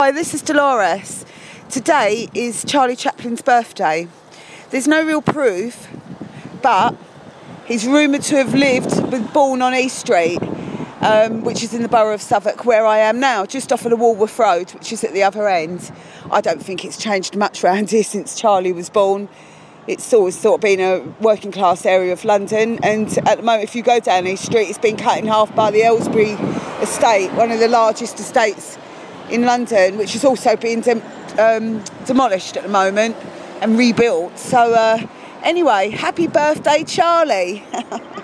It's Charlie Chaplin's birthday today. I'm on the road he supposedly lived on East Street in Southwark